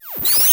doorClose_001.ogg